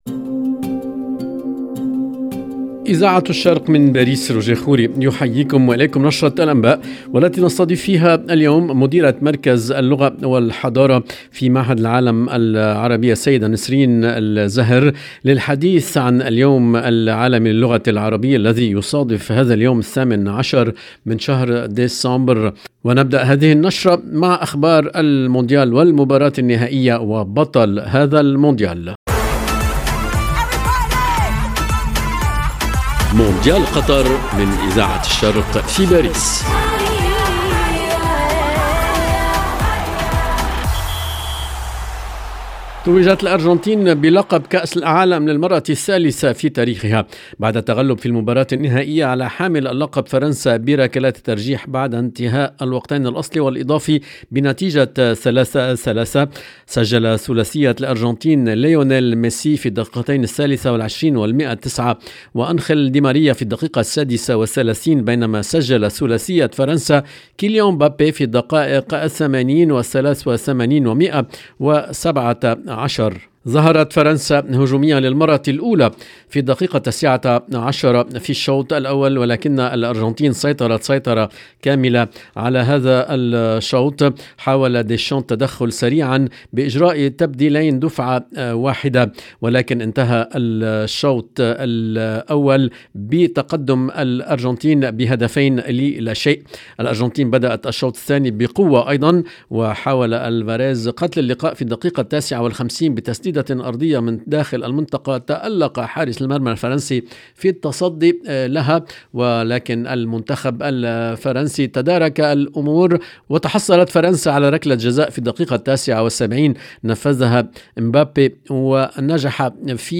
EDITION DU JOURNAL DU SOIR EN LANGUE ARABE DU 18/12/2022